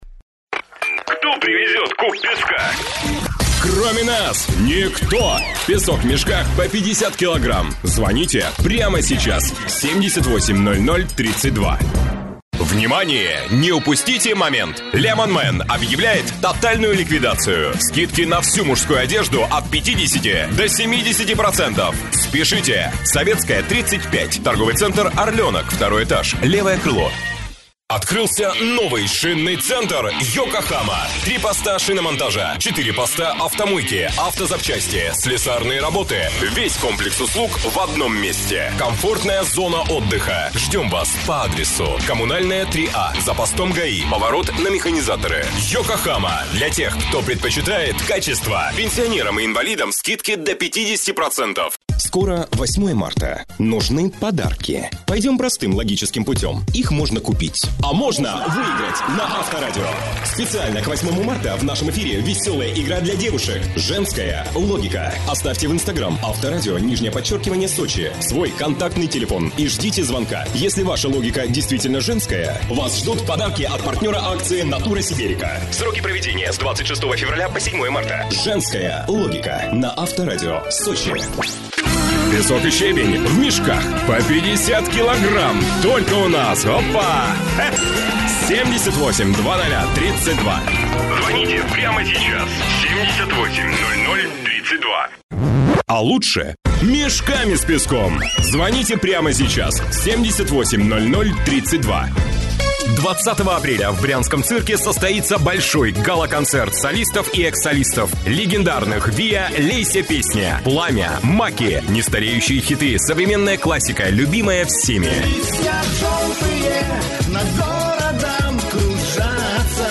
INVOTONE CM400L звуковая карта roland q55 Комната пирамиды 50
Демо-запись №1 Скачать